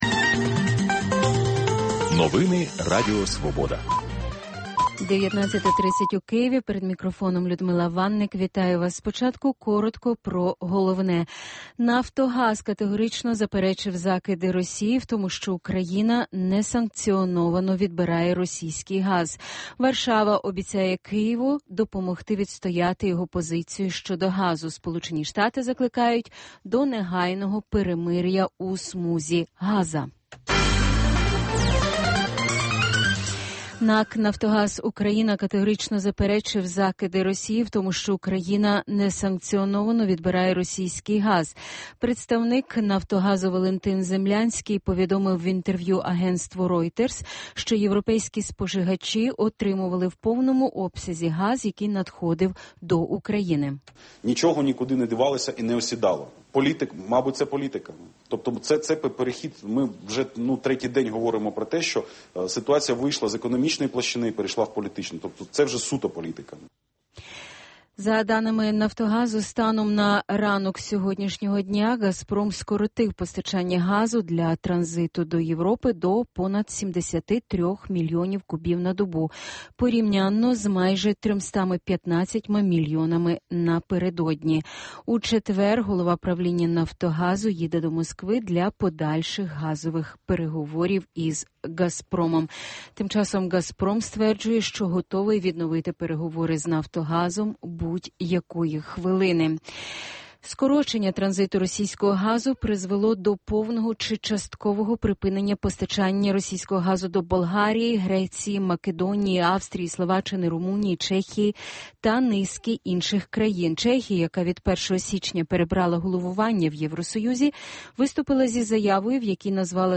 Інтелектуальна дуель у прямому ефірі. Дискусія про головну подію дня, що добігає кінця.